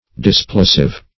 Displosive \Dis*plo"sive\, a.